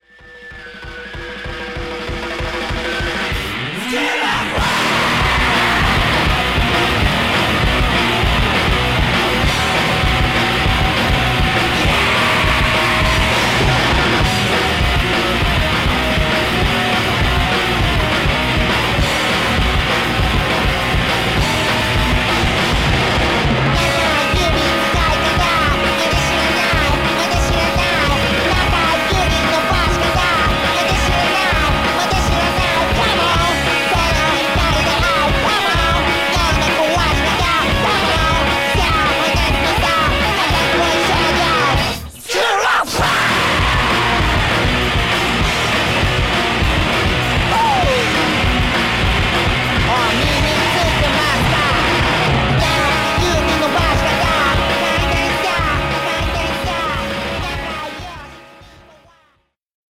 アグレッシブなハードコア・パンク・サウンド。